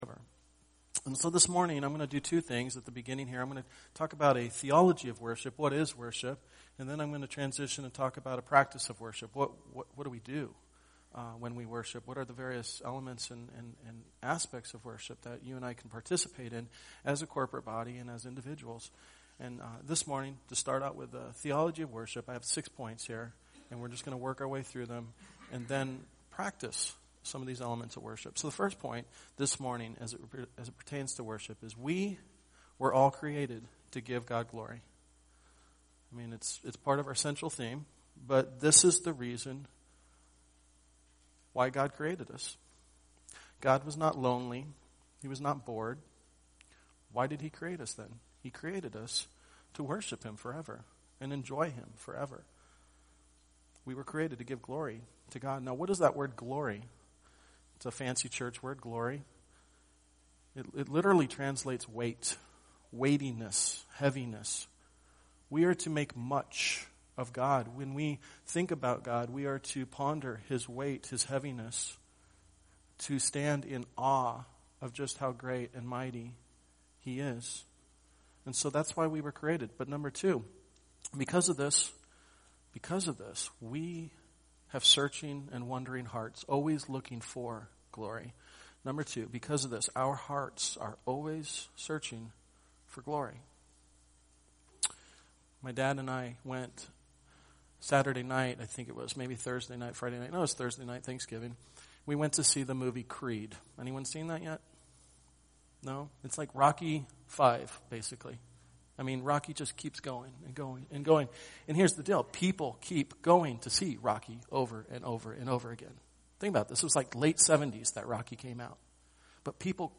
This sermon had worship songs and other congregational interactions interspersed throughout. As we could not include those sections, the recording may seem to jump a bit from point to point.